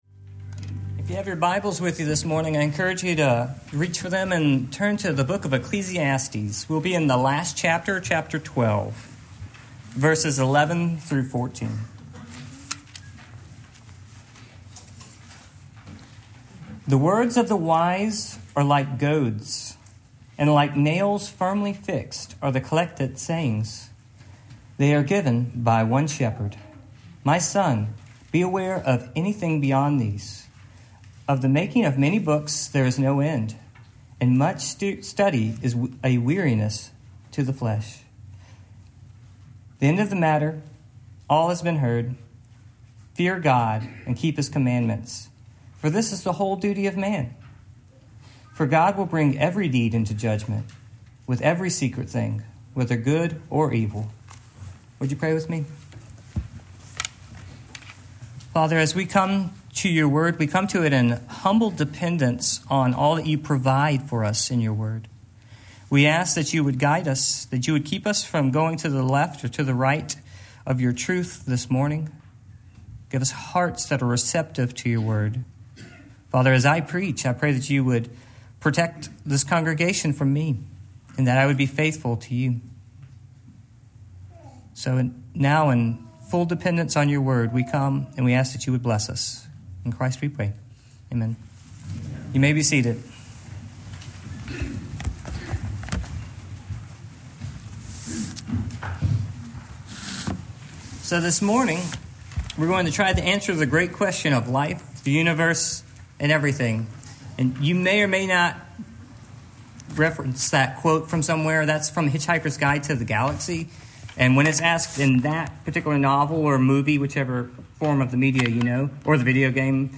Ecclesiastes 12:11-14 Service Type: Morning Main Point